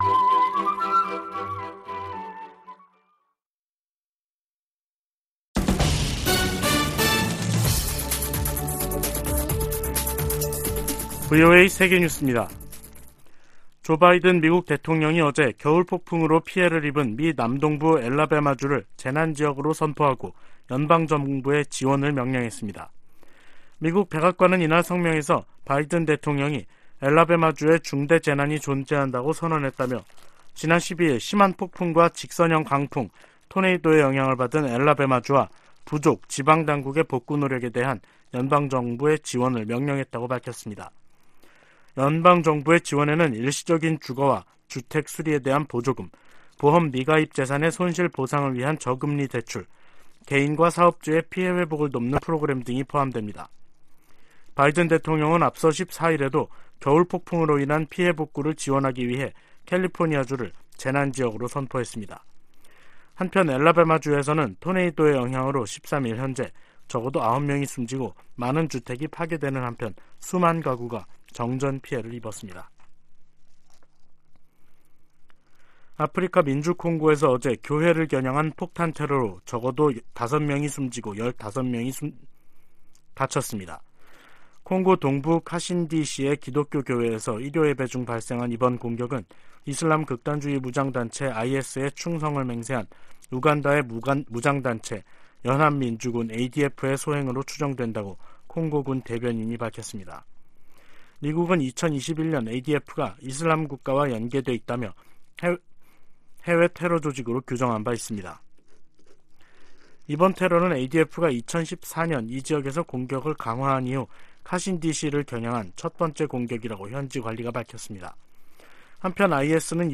VOA 한국어 간판 뉴스 프로그램 '뉴스 투데이', 2023년 1월 16일 2부 방송입니다. 조 바이든 미국 대통령과 기시다 후미오 일본 총리가 워싱턴에서 열린 정상회담에서 한반도 비핵화와 북한의 납치 문제 등을 논의했습니다. 지난해 미국과 한국 정부의 대북 공조가 더욱 강화됐다고 미국 의회조사국이 평가했습니다.